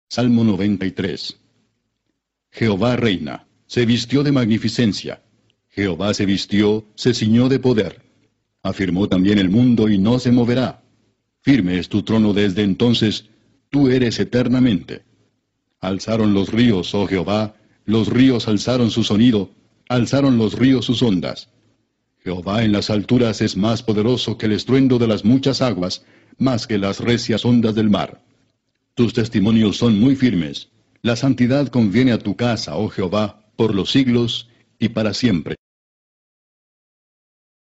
Lectura de la Biblia